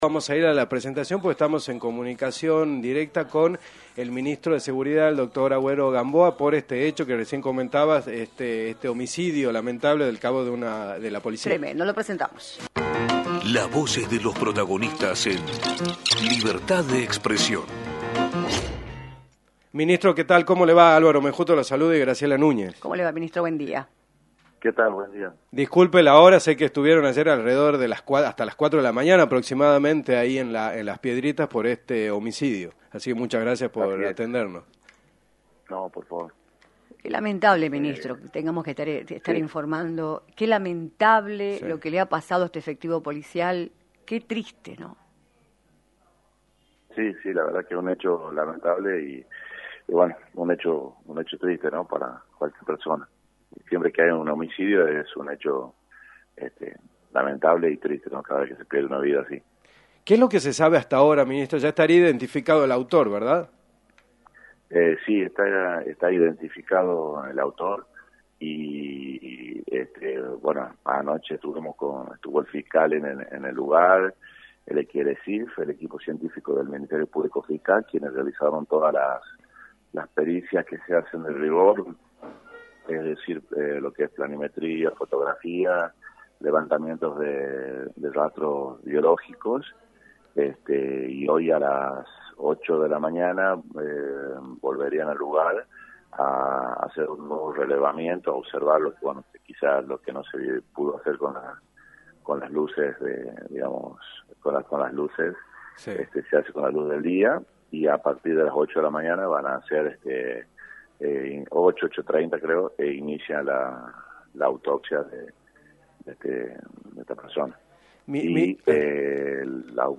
Eugenio Agüero Gamboa, Ministro de Seguridad, informó en “Libertad de Expresión”, por la 106.9